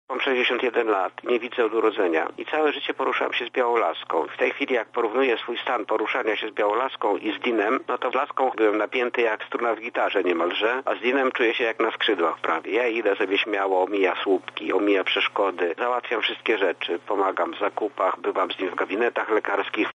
posiadacz psa przewodnika.